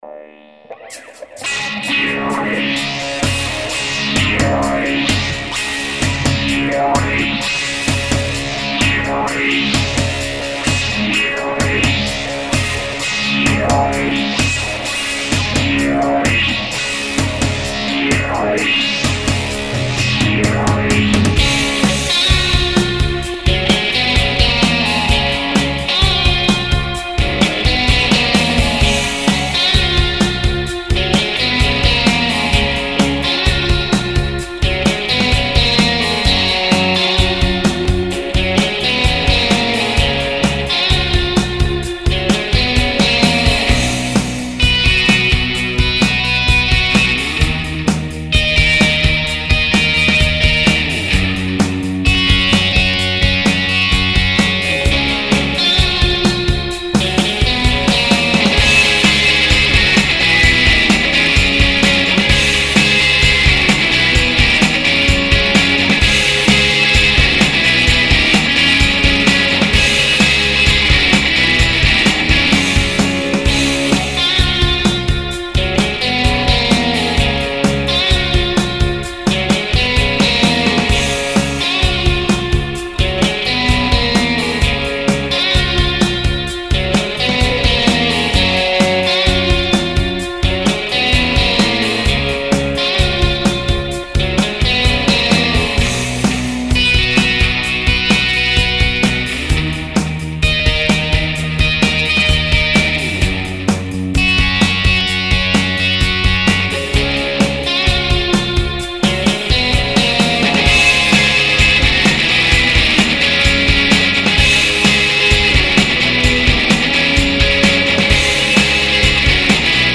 guitar
me on guitar